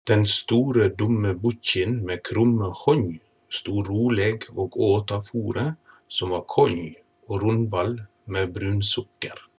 Ny side 1 Lytt til dialekteksemplene nedenfor.